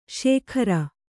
♪ śekhara